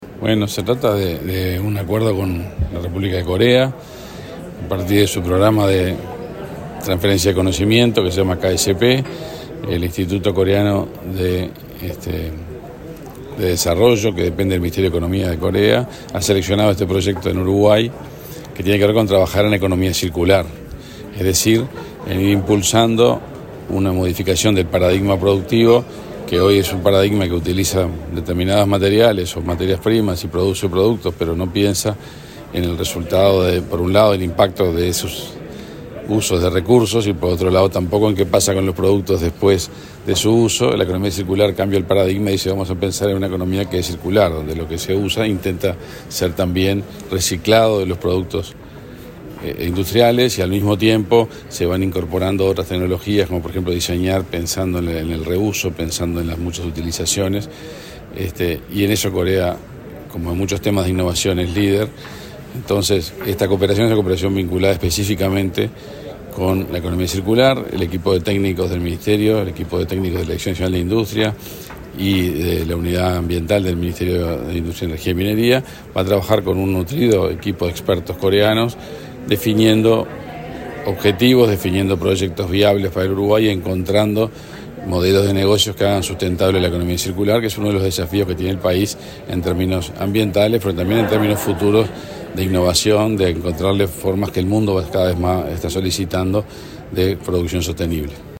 Declaraciones del ministro de Industria, Omar Paganini
El ministro de Industria, Omar Paganini, dialogó con Comunicación Presidencial antes de participar del seminario sobre el fortalecimiento de capacidades en el desarrollo de políticas públicas para la vinculación de la economía circular con la digitalización en la industria.